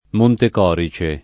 Montecorice [ montek 0 ri © e ]